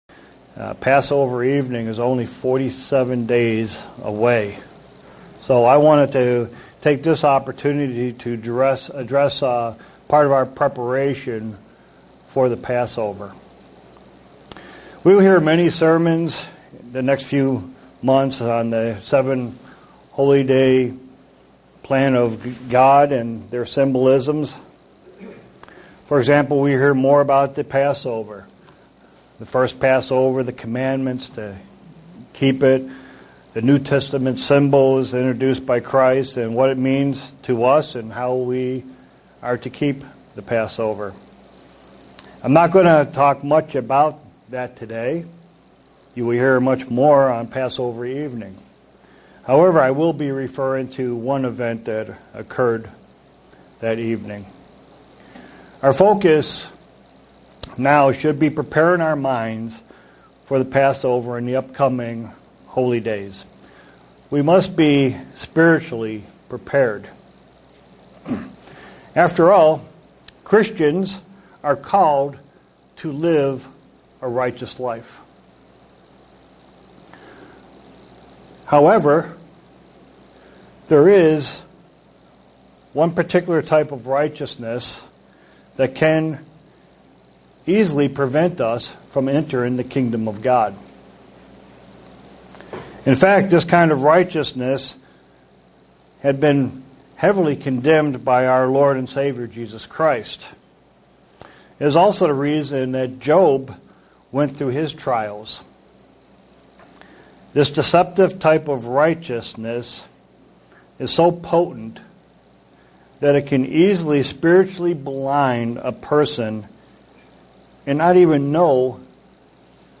Print How Christ lived is the example and the cure for selfrighteousness. sermon Studying the bible?